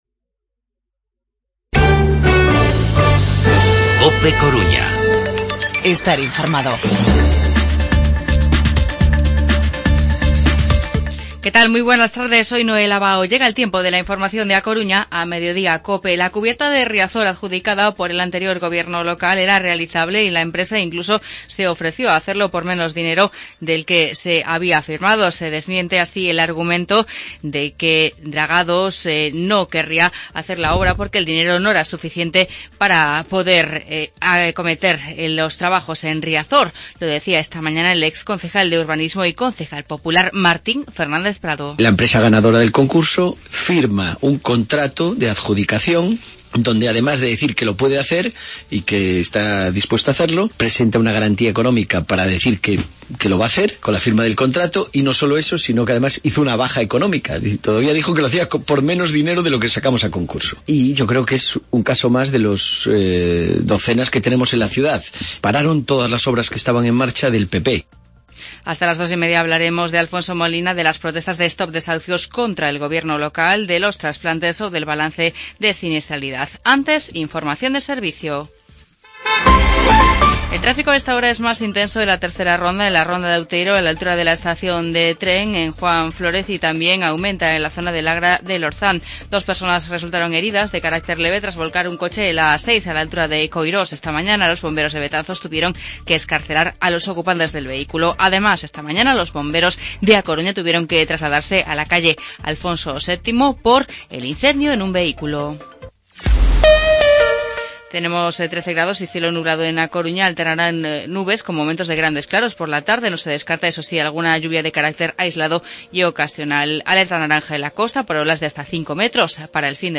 Informativo Mediodía COPE Coruña viernes, 10 de febrero de 2017